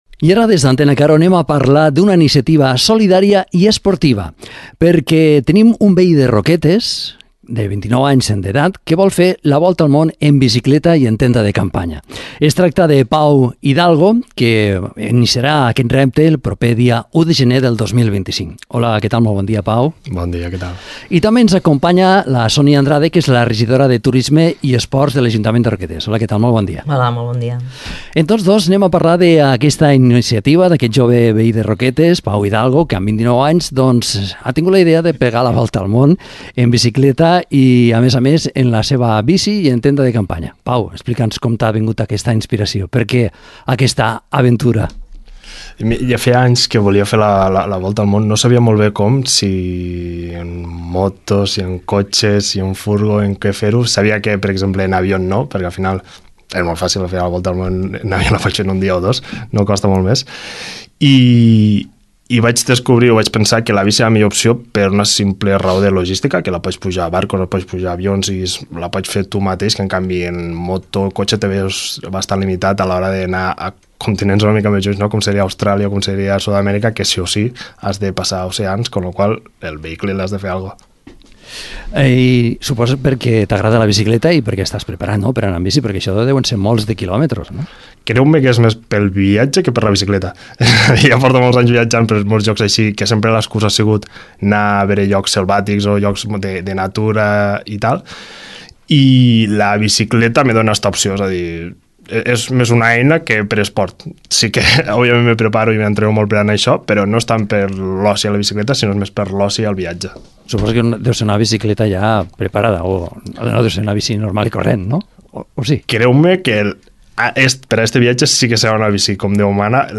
A l’entrevista, també ens acompanya la regidora de Turisme i Esport de Roquetes, Sonia Andrade , per donar-li des de l’Ajuntament tot el suport a la seva iniciativa solidària, que portarà el nom de Roquetes per tot el món.